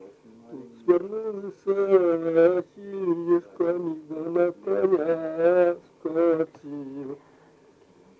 Мягкое цоканье (совпадение литературных аффрикат /ц/ и /ч’/ в мягком /ц’/)
/а-впо-моо”-гуу йоо-муу в’е”д’ е”-счо дваа” ба-га-ты-р’а”-э:
А”-жкак о-л’оо”-шаа поо-поо”-в’ииц’ ды-до-брыыы”-н’у-шка:-в’ед’/